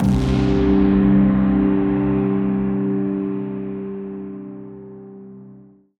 Synth Impact 07.wav